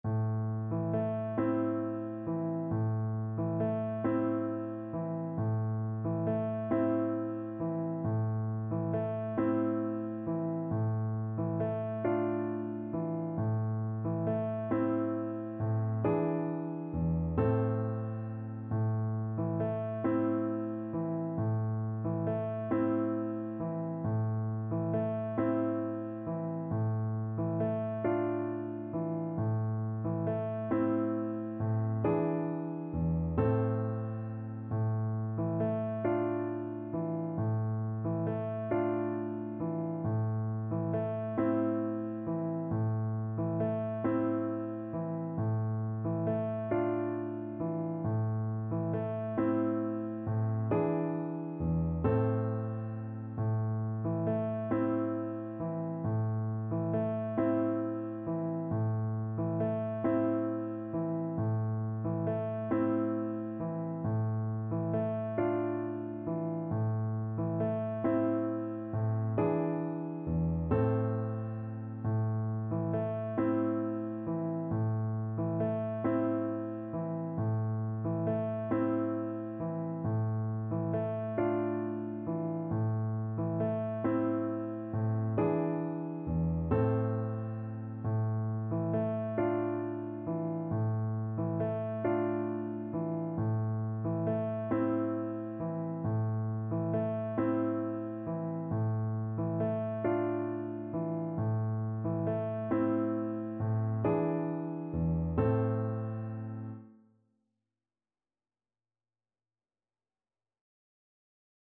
Play (or use space bar on your keyboard) Pause Music Playalong - Piano Accompaniment Playalong Band Accompaniment not yet available reset tempo print settings full screen
A minor (Sounding Pitch) (View more A minor Music for Recorder )
Gently rocking .=c.45